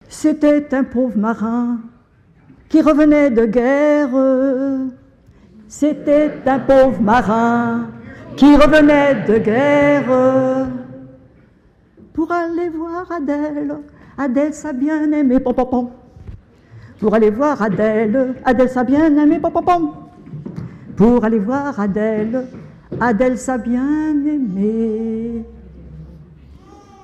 Genre strophique
chansons traditionnelles lors d'un concert associant personnes ressources et continuateurs